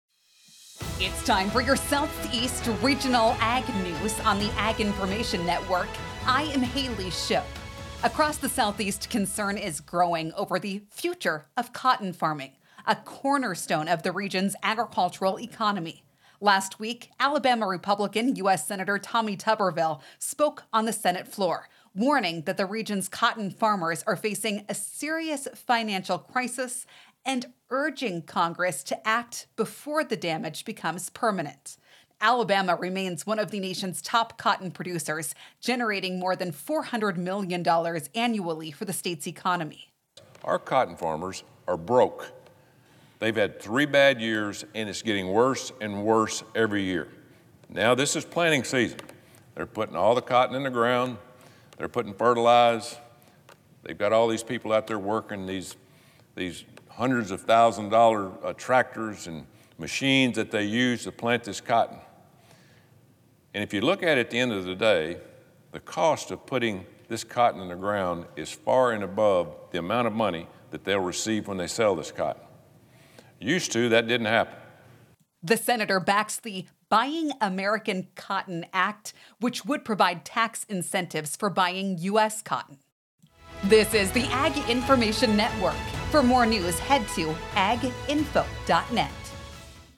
Monday Apr 20th, 2026 53 Views Southeast Regional Ag News
Last week, Alabama Republican U.S. Senator Tommy Tuberville spoke on the Senate floor, warning that the region’s cotton farmers are facing a serious financial crisis and urging Congress to act before the damage becomes permanent.